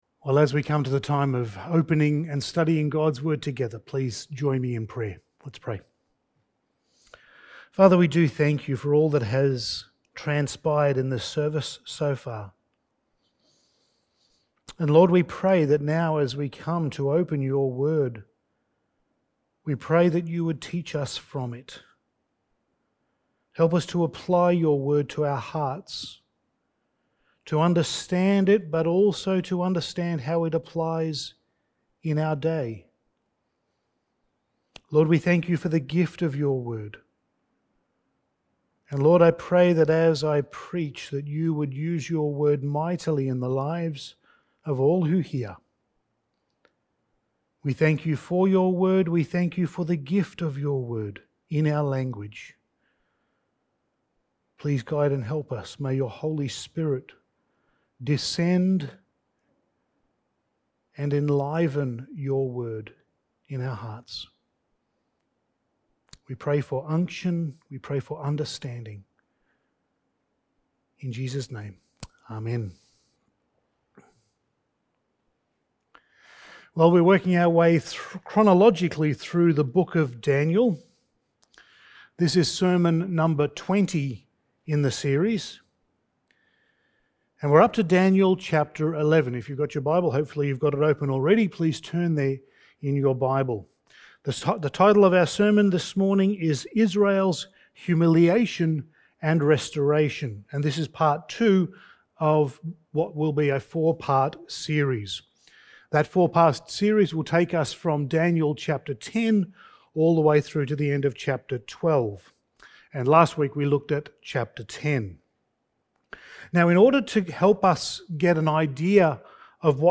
Passage: Daniel 11:1-35 Service Type: Sunday Morning